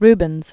(rbənz), Peter Paul 1577-1640.